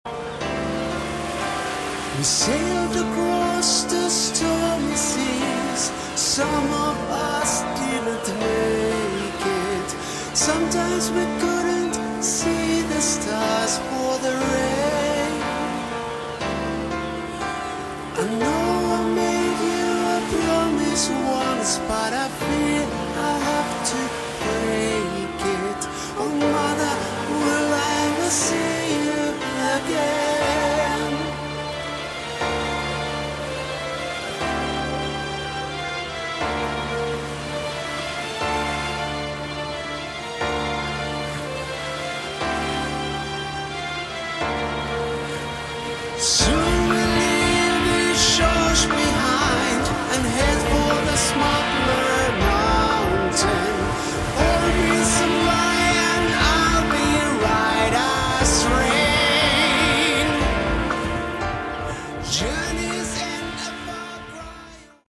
Category: Hard Rock
lead and backing vocals
guitars, keys, backing vocals
drums
bass